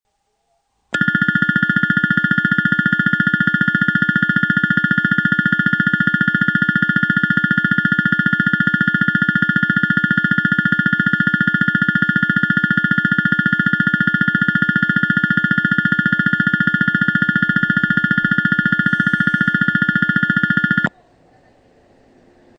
接近寺にカラカラベルが鳴るのみです。放送はありません。
上下線共に進入列車と被り、上り線は車両停止位置と被ります。
スピーカー TOA丸型
接近ベル ベル PCM